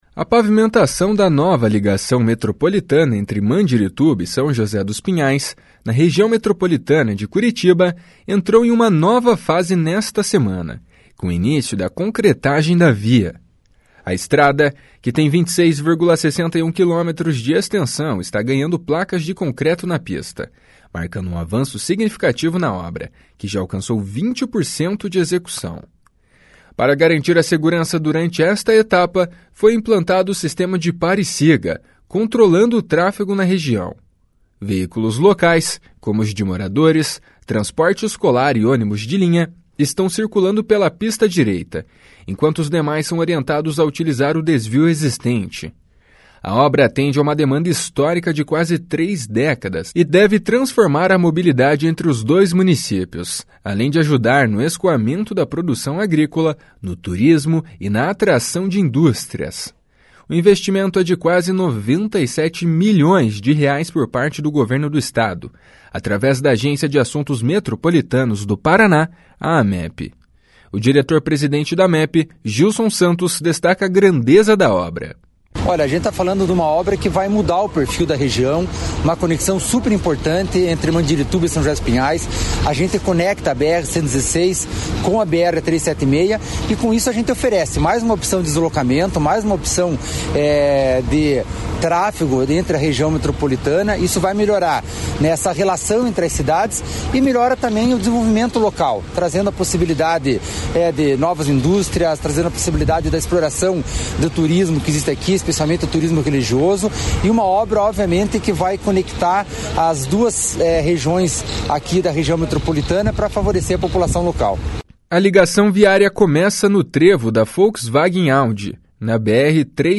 O diretor-presidente da Amep, Gilson Santos, destaca a grandeza da obra. // SONORA GILSON SANTOS //